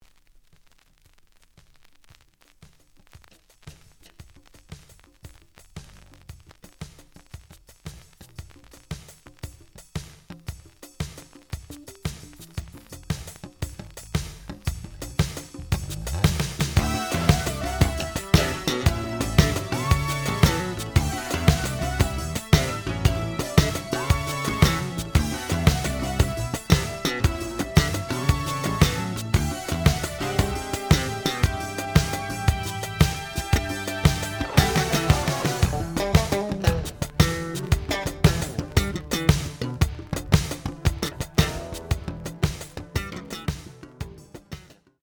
The audio sample is recorded from the actual item.
●Genre: Funk, 80's / 90's Funk
Slight noise on beginning of B side, but almost good.